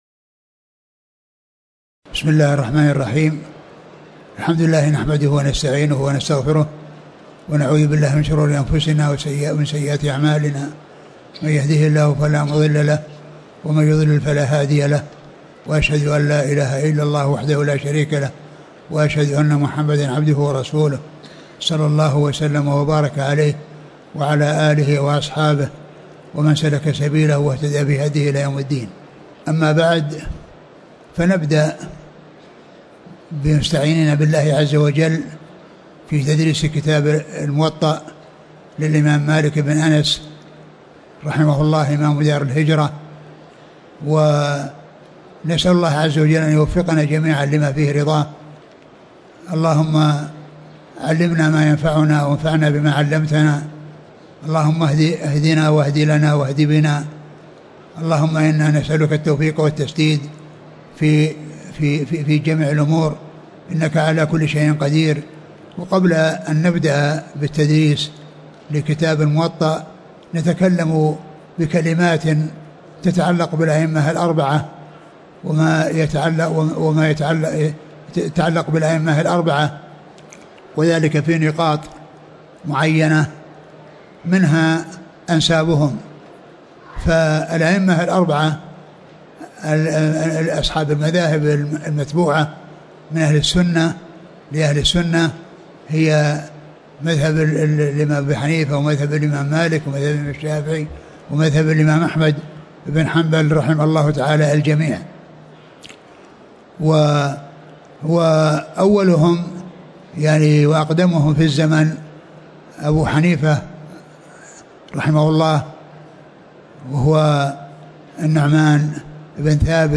تاريخ النشر ٢ جمادى الآخرة ١٤٤١ المكان: المسجد النبوي الشيخ: فضيلة الشيخ عبدالمحسن بن حمد العباد فضيلة الشيخ عبدالمحسن بن حمد العباد 01المقدمة